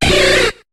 Cri de Caratroc dans Pokémon HOME.